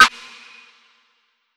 TM-88 Snare #15.wav